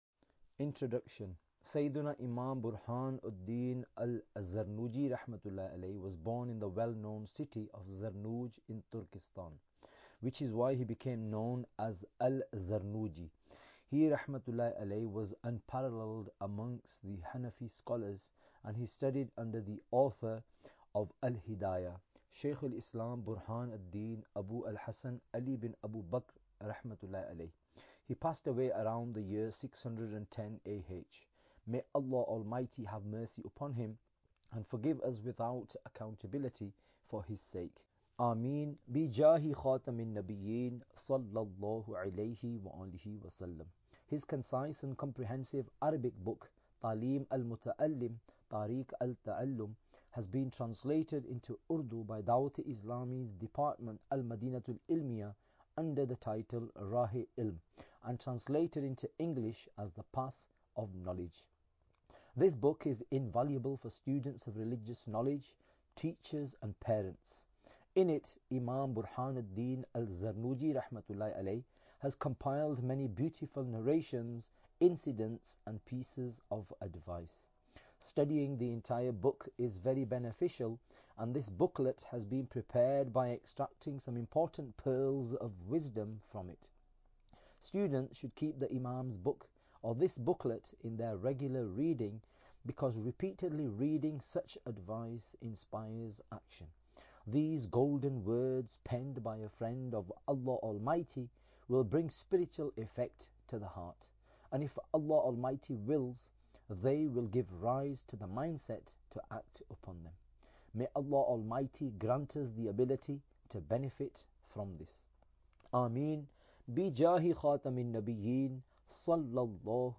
Audiobook – 72 Madani Pearls From The Book Raah e Ilm (English)